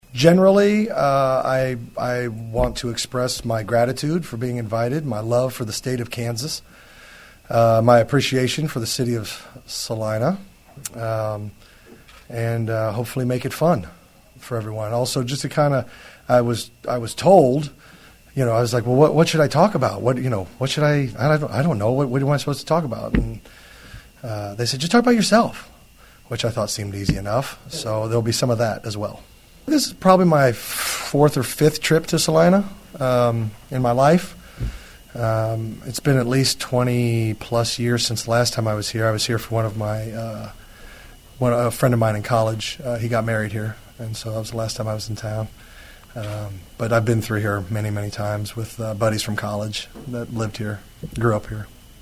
Prior to his speaking engagement Riggle met with the media.